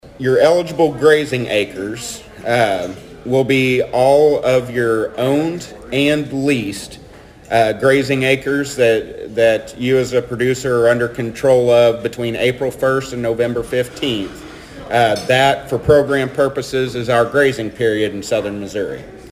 The Thayer and Mammoth Spring Rotary met Wednesday for their weekly meeting.